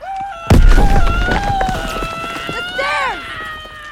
PLAY cod zombie scream sound effect
cod-scream.mp3